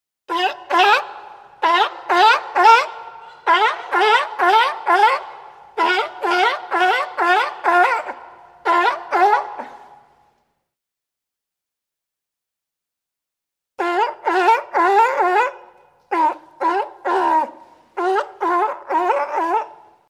Звук с визгом тюленя